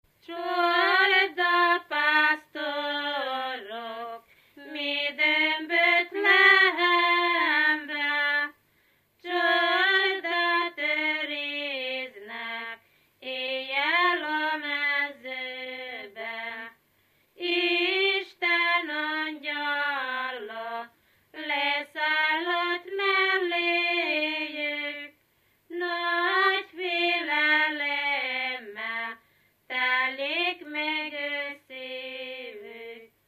Dunántúl - Verőce vm. - Lacháza
Műfaj: Népének
Stílus: 7. Régies kisambitusú dallamok